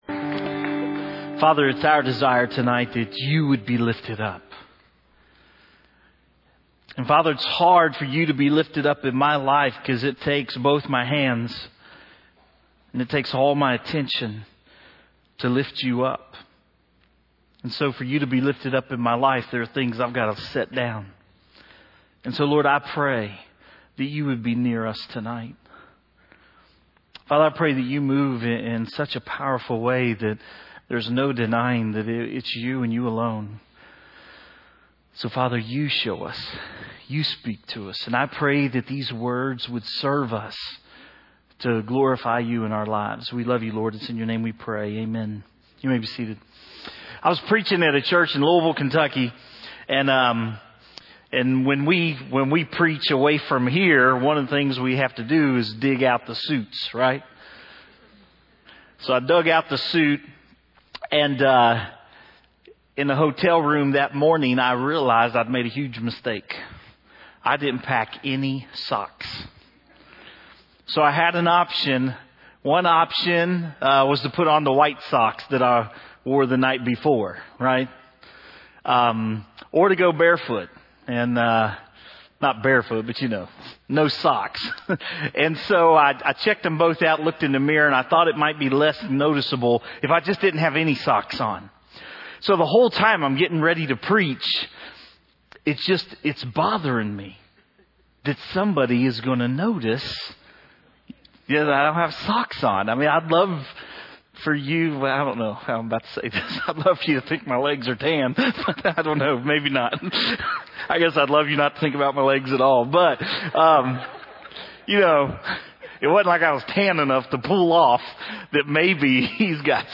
Online Audio Sermons